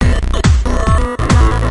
descargar sonido mp3 ritmo 8